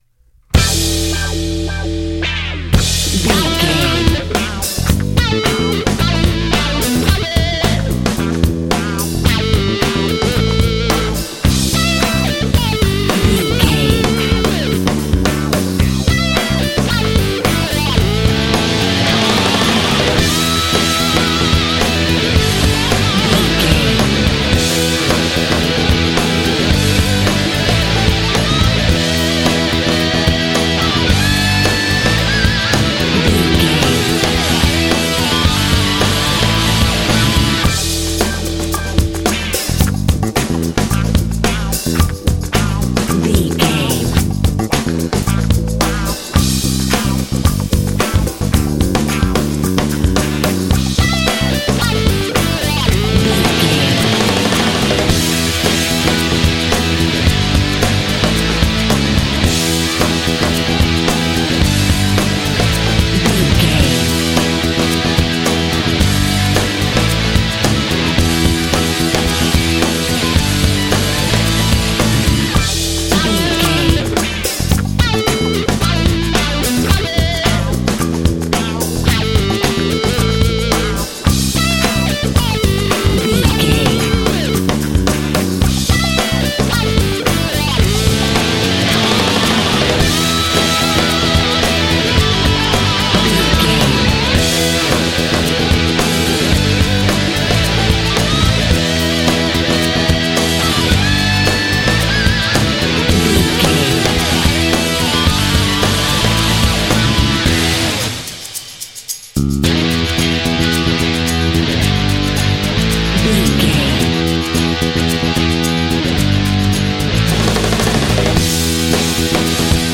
Fast paced
Aeolian/Minor
heavy
groovy
energetic
drums
electric guitar
bass guitar
heavy metal
alternative rock
classic rock